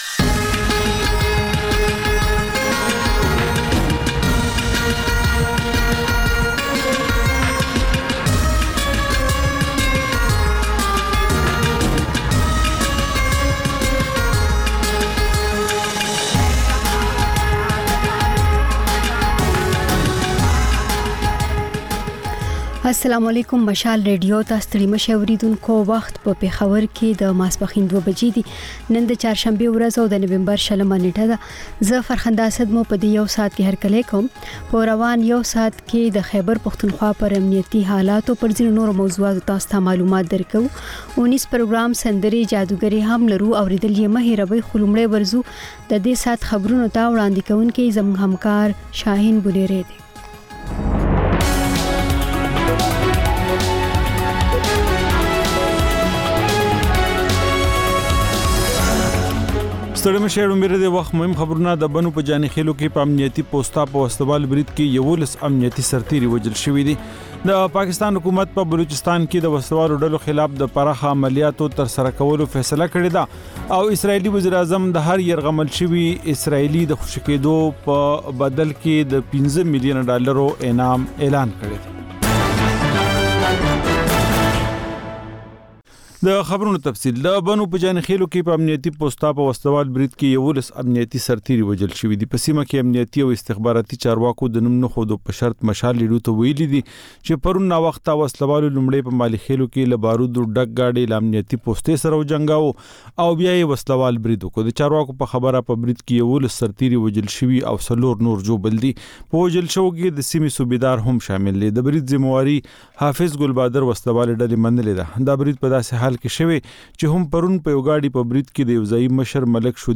په دې خپرونه کې لومړی خبرونه او بیا ځانګړې خپرونې خپرېږي.